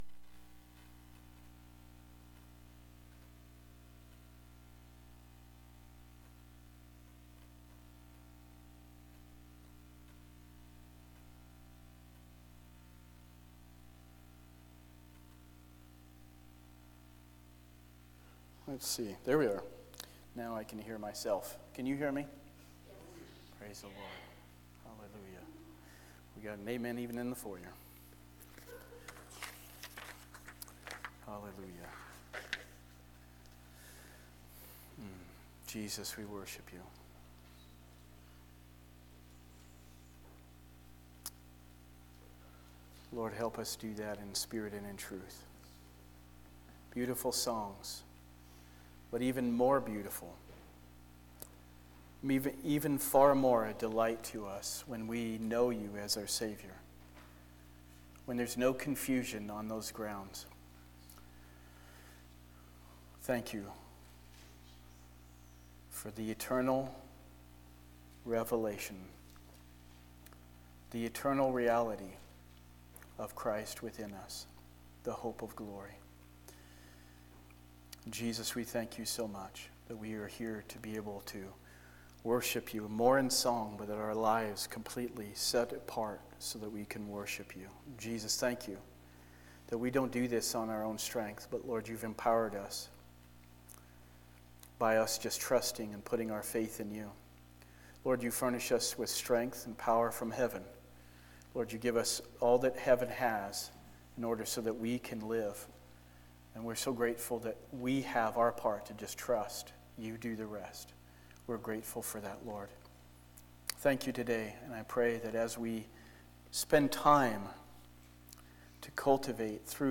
Sermons by Abundant Life Assembly … continue reading 319 episodes # Religion # Christianity # Abundant Life Assembly